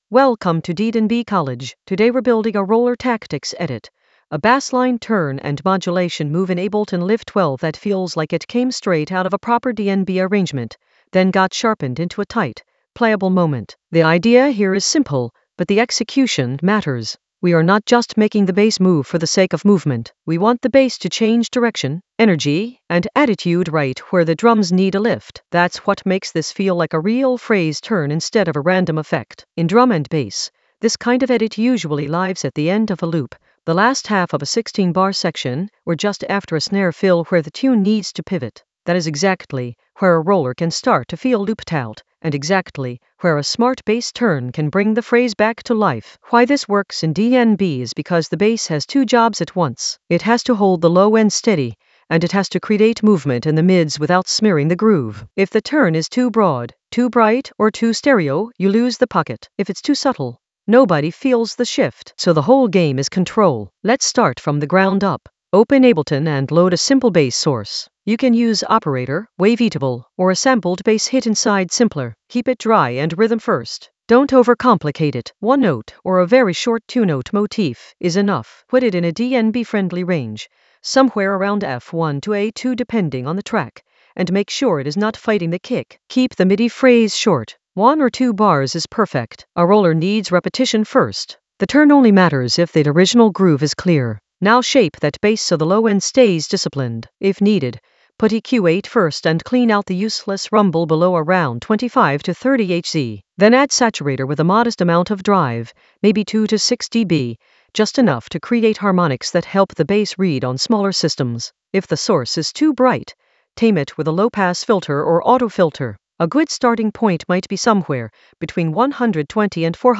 An AI-generated intermediate Ableton lesson focused on Roller Tactics edit: a bassline turn modulate from scratch in Ableton Live 12 in the Sampling area of drum and bass production.
Narrated lesson audio
The voice track includes the tutorial plus extra teacher commentary.